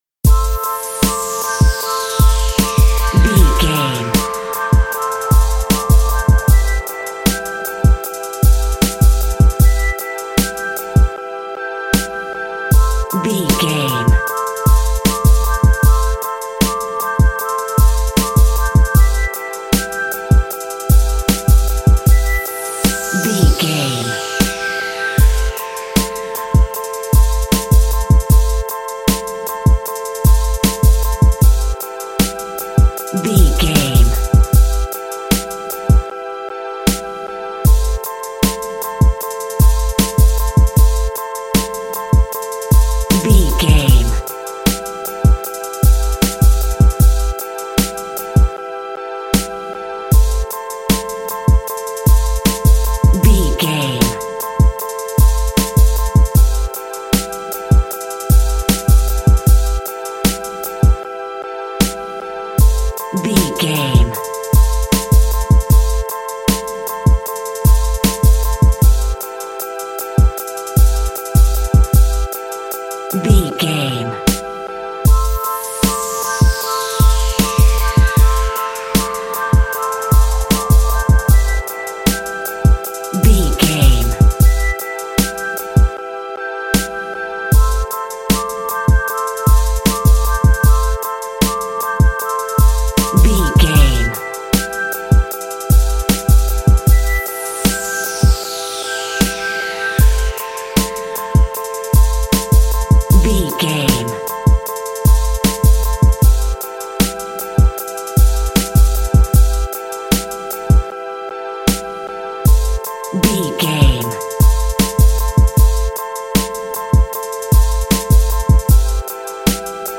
Ionian/Major
drum machine
synthesiser
bouncy
funky